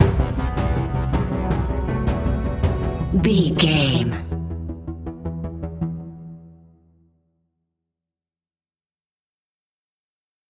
In-crescendo
Aeolian/Minor
ominous
haunting
eerie
synthesizer
percussion
Horror Synths